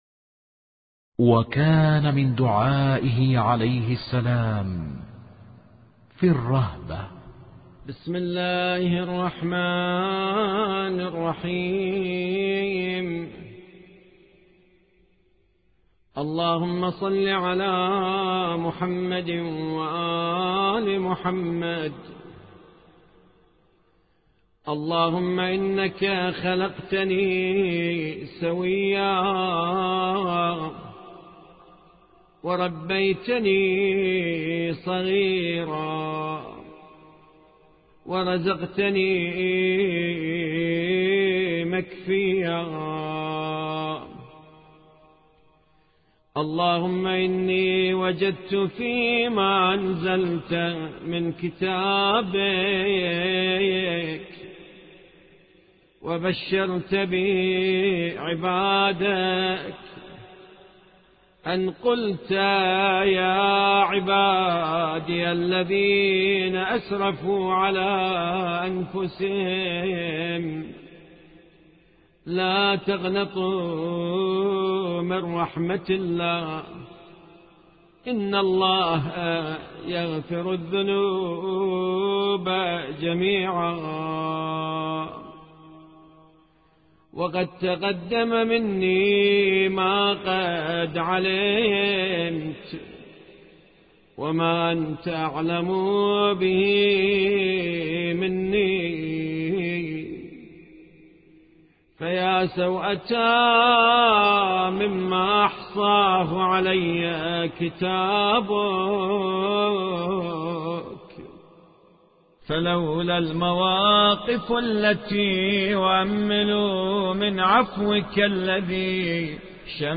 الأدعية الصحيفة السجادية